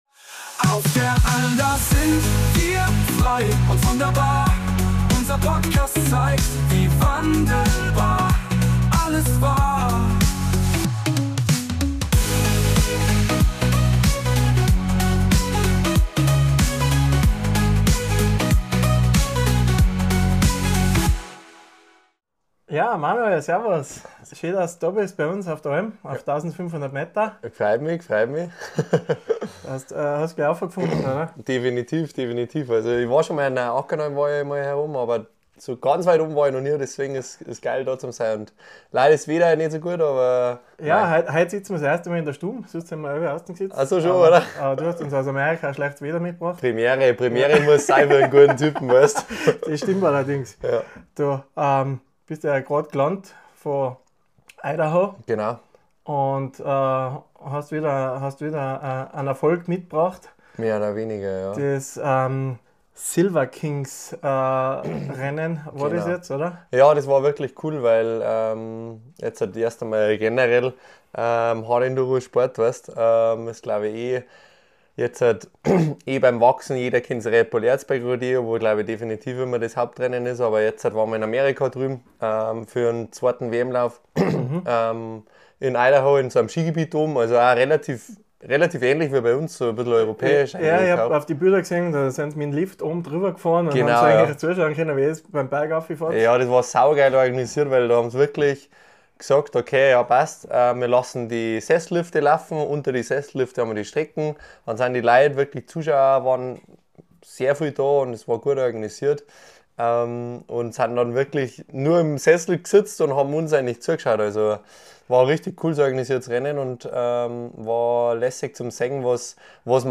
Aufgenommen auf der Steinerkaser Alm in Thiersee